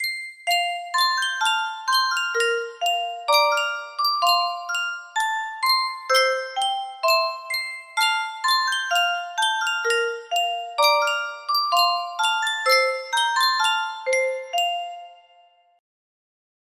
Yunsheng Music Box - When You and I Were Young, Maggie 6223 music box melody
Full range 60